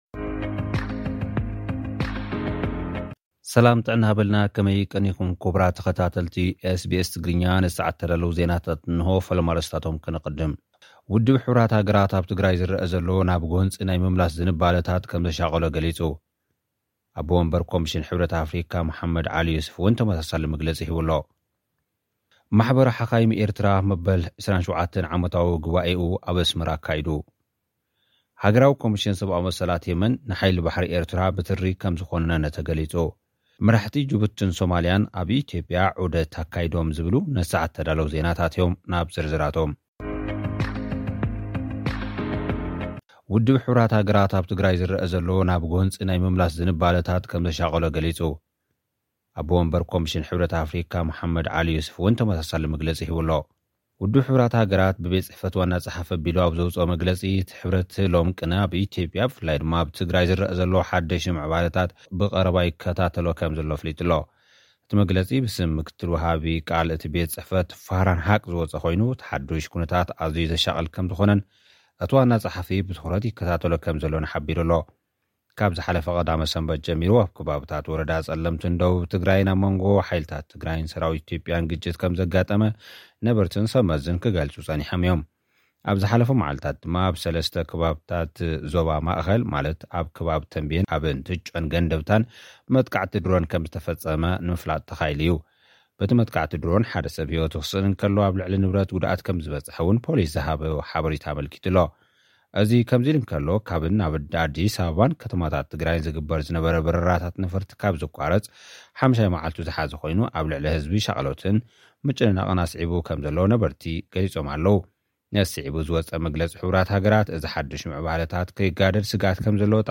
ውድብ ሕቡራት ሃገራት ኣብ ትግራይ ዝረአ ዘሎ ናብ ጐንፂ ናይ ምምላስ ዝንባለታት ከምዘሻቕሎ ገሊፁ። ጸብጻብ